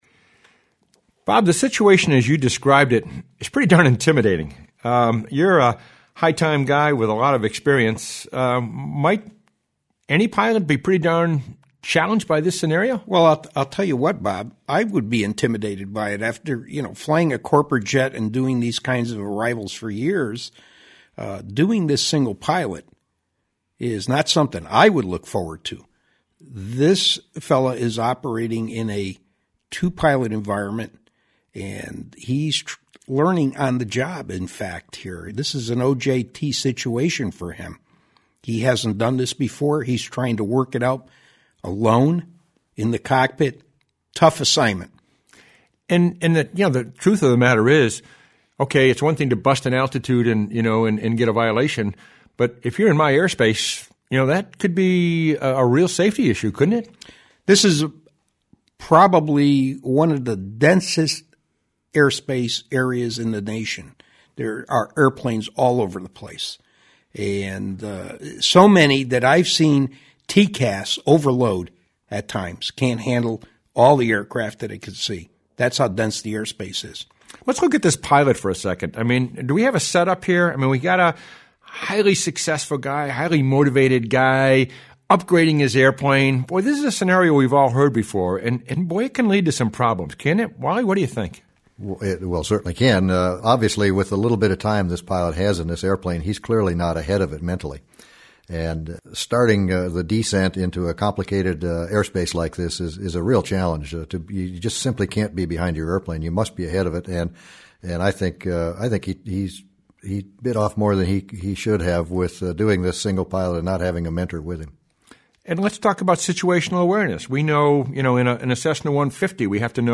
#76 Descend via STAR roundtable.mp3